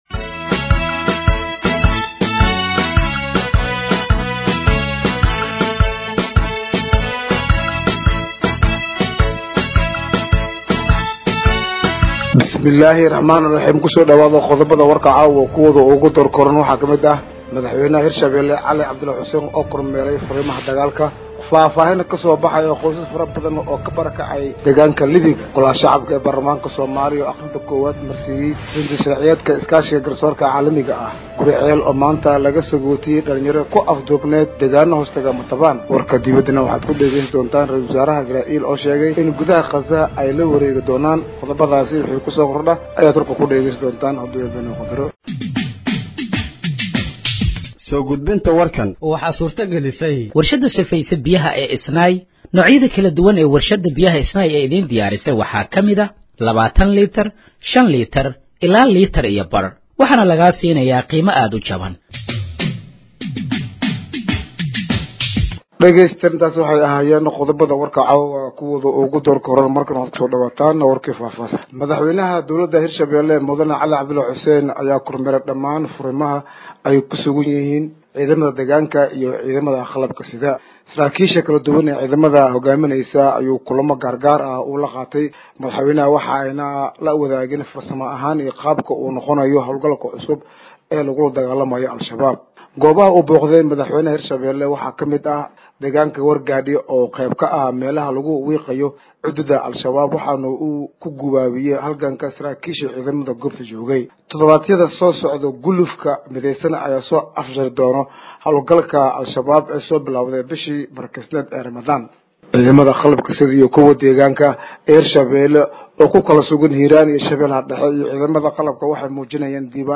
Dhageeyso Warka Habeenimo ee Radiojowhar 19/05/2025